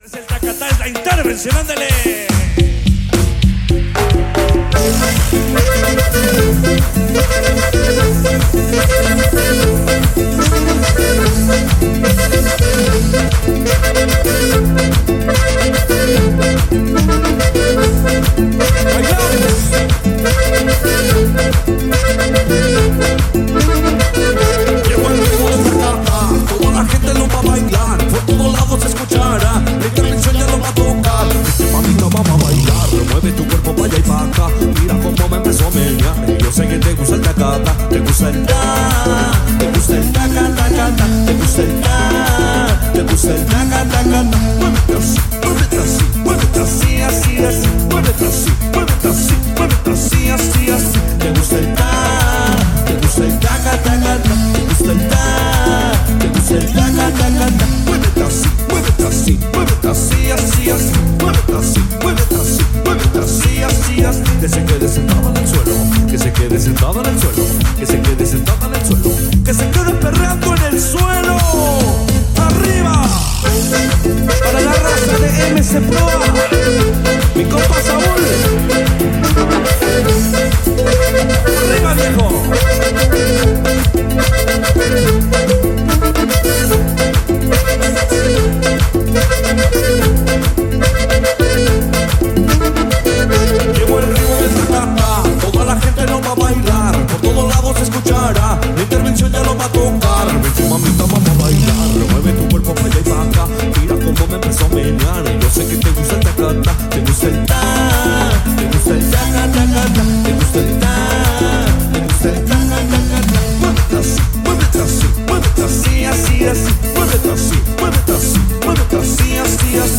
El estilo caracteristico de norteño con sax